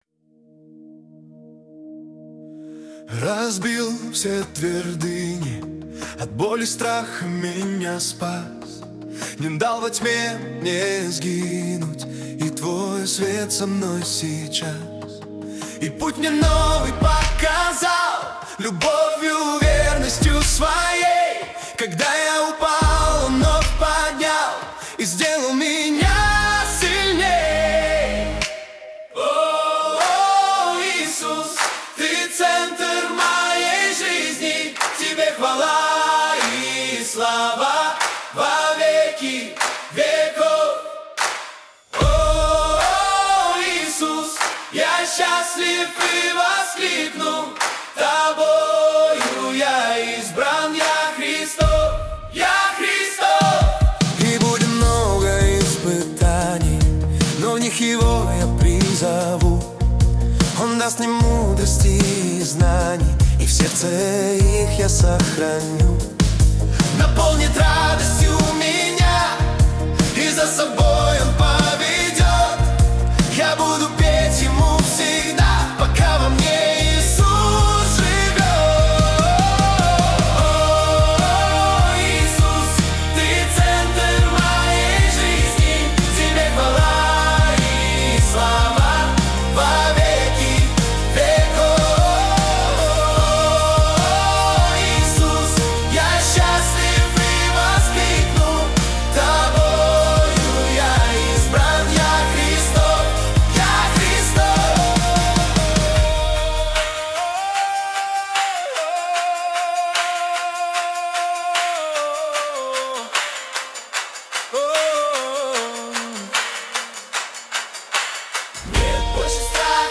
песня ai